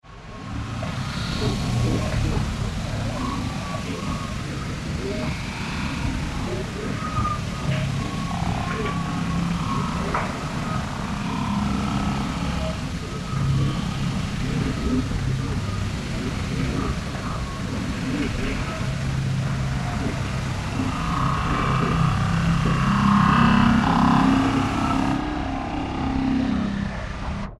Звуки аномалий
Звук аномалий на фоне